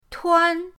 tuan1.mp3